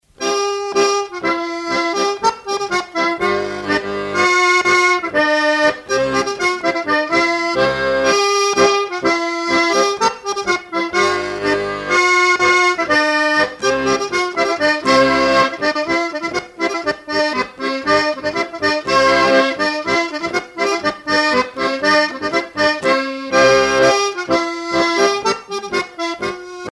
danse : trois coups de talon
Pièce musicale éditée